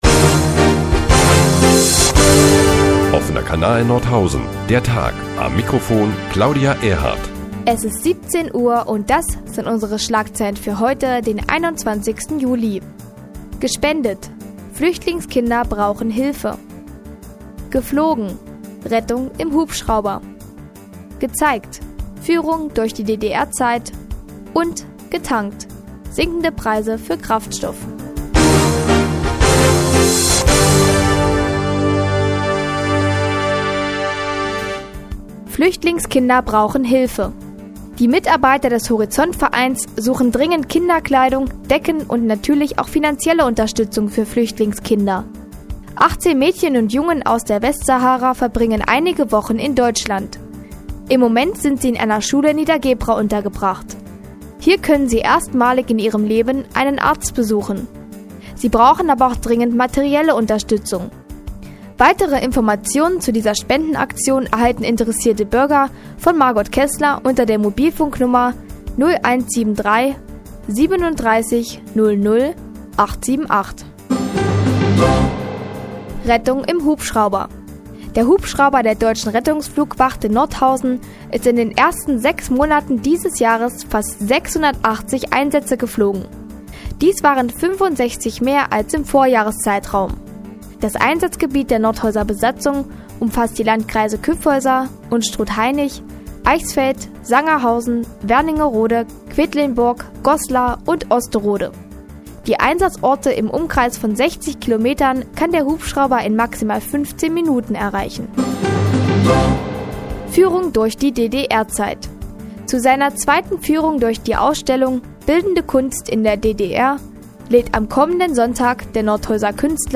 Die tägliche Nachrichtensendung des OKN ist nun auch in der nnz zu hören. Heute unter anderem mit der Bitte um Spenden für Flüchtlingskinder und niedrigen Spritpreisen trotz Ferienzeit.